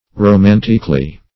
romanticly - definition of romanticly - synonyms, pronunciation, spelling from Free Dictionary Search Result for " romanticly" : The Collaborative International Dictionary of English v.0.48: Romanticly \Ro*man"tic*ly\, adv.